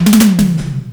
FILLTOMEL2-L.wav